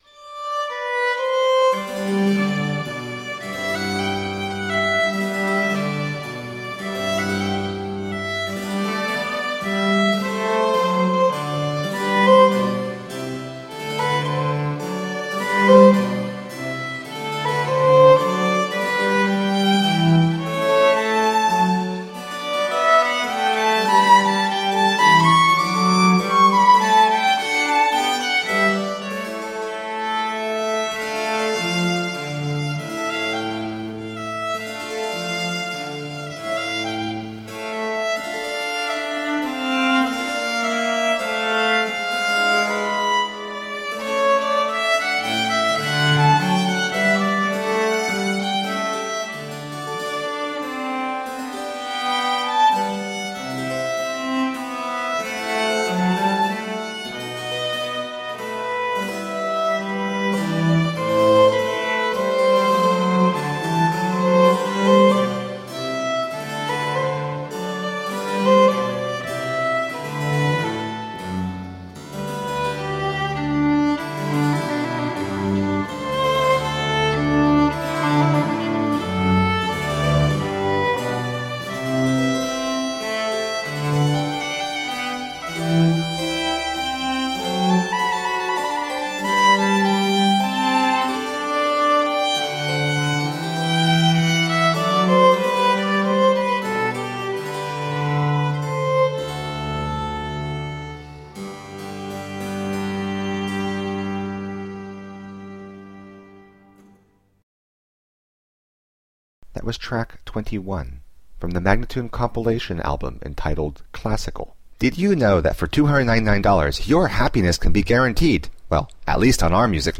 Sonata in e minor for Violin and Continuo